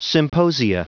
Prononciation du mot symposia en anglais (fichier audio)
symposia.wav